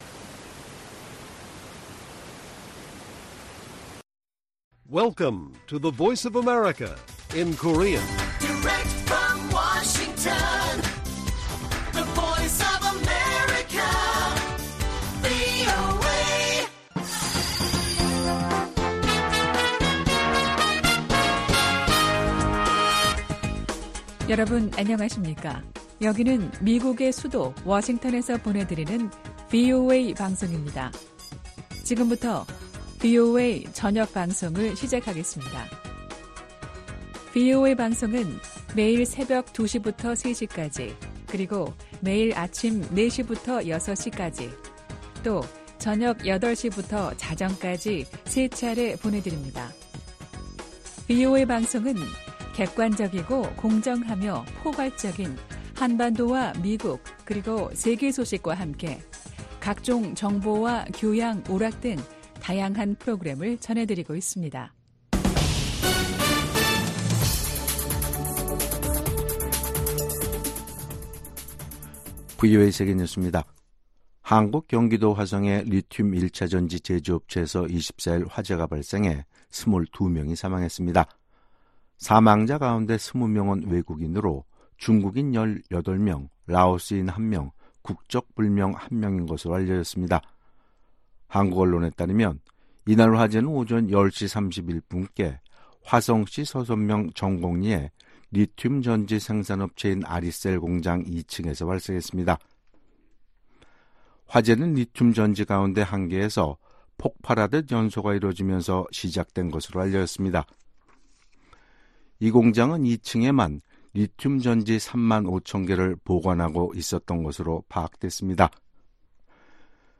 VOA 한국어 간판 뉴스 프로그램 '뉴스 투데이', 2024년 6월 24일 1부 방송입니다. 북한 동창리 서해위성발사장에 대규모 굴착 작업 흔적이 들어났습니다. 한국 대통령실은 러시아가 북한에 고도의 정밀무기를 제공하면 우크라이나에 무기를 지원하겠다고 경고했습니다. 미국과 한국 외교장관이 전화 통화를 갖고 북한과 러시아가 체결한 새 협정을 규탄했습니다.